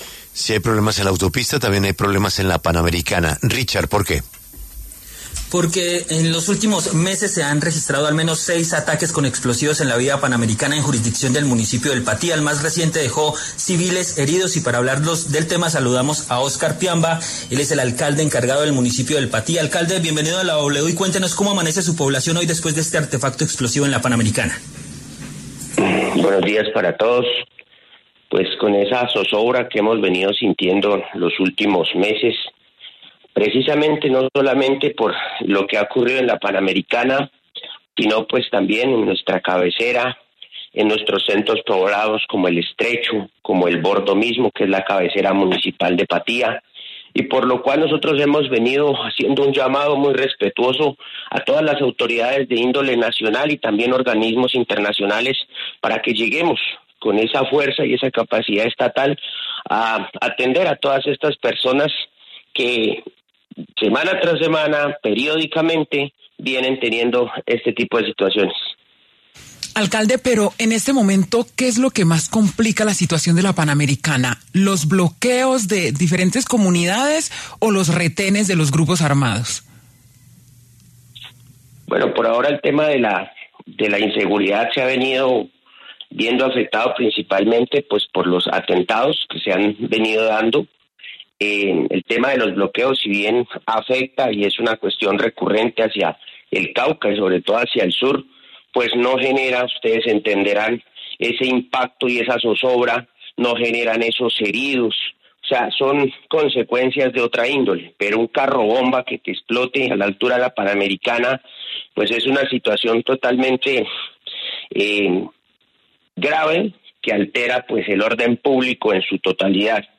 Óscar Piamba, alcalde encargado pasó por los micrófonos de La W Radio para rechazar la escalada violenta en el territorio.
El alcalde local encargado, Óscar Piamba, en entrevista con La W reportó que en los últimos meses han tenido lugar por lo menos seis ataques.